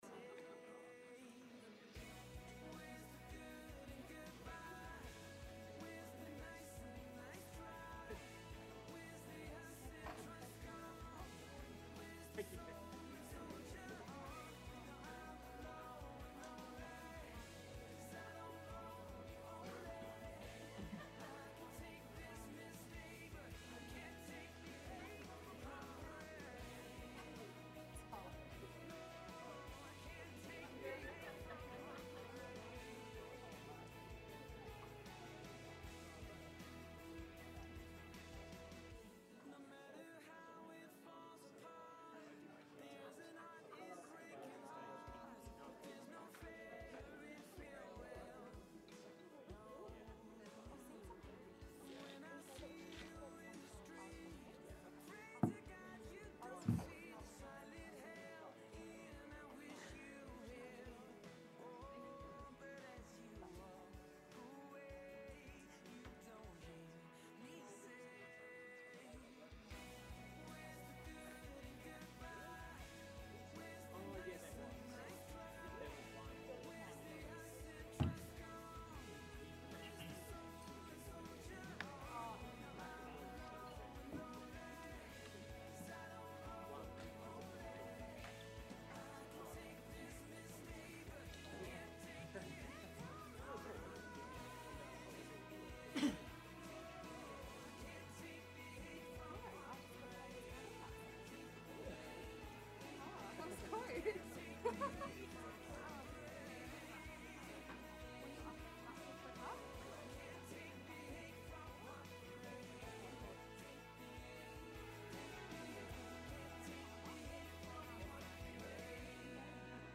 Took place at: Hilton Brisbane & Online